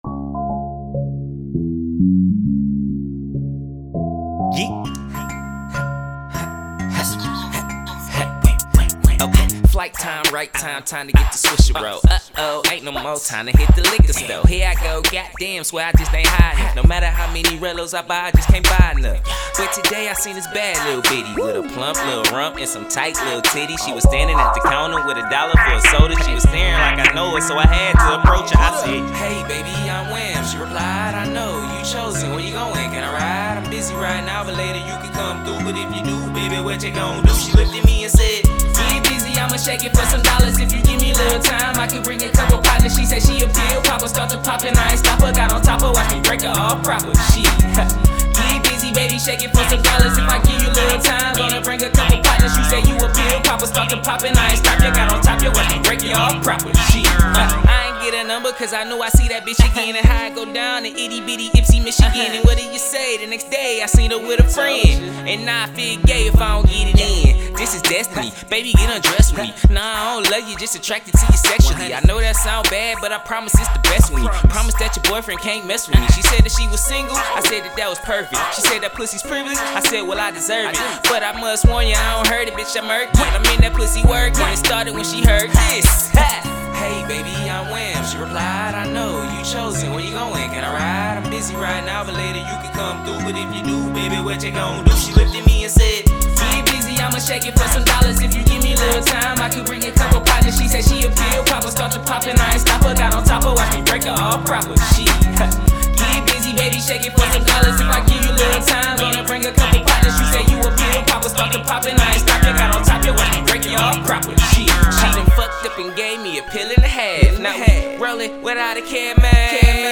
Hiphop
SUMMER BANGER